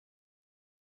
voicepowers_shout02_0010f4e7_1